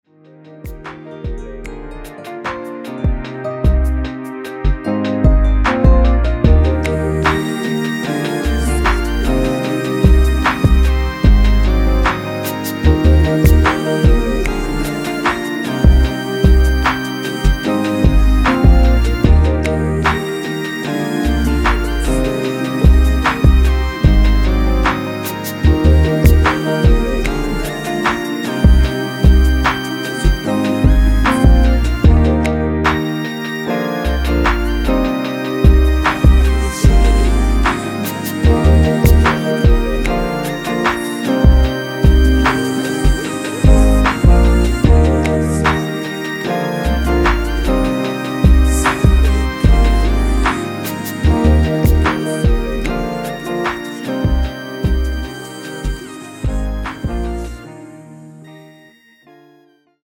원키 코러스 포함된 MR 입니다.(미리듣기 참조)
Eb
앞부분30초, 뒷부분30초씩 편집해서 올려 드리고 있습니다.
중간에 음이 끈어지고 다시 나오는 이유는